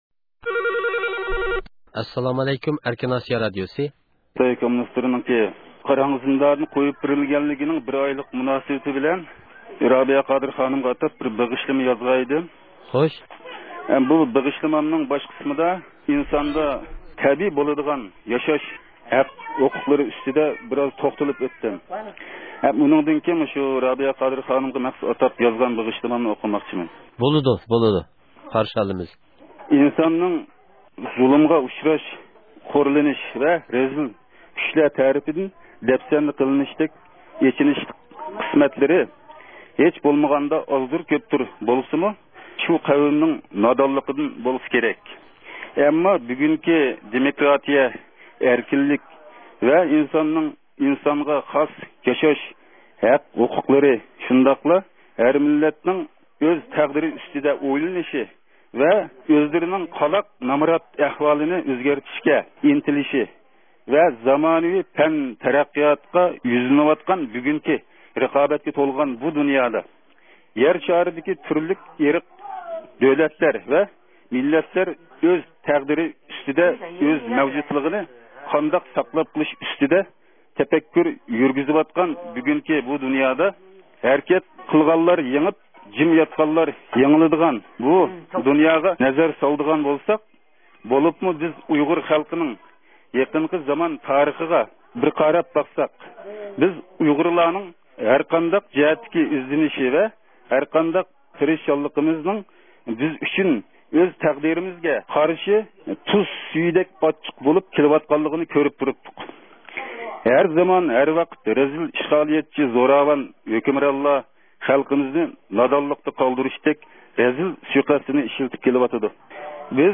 ئۇيغۇر ئېلىدىن بىر ياش رادىئومىزنىڭ ھەقسىز لىنىيىسىگە تېلېفون ئۇرۇپ، رابىيە قادىرنىڭ ھۆرلۈككە ئېرىشكەنلىكىنىڭ بىر ئايلىقى مۇناسىۋىتى بىلەن ئۆزىنىڭ رابىيە قادىرغا ئاتاپ يازغان بېغىشلىمىسىنى ئوقۇپ بەردى.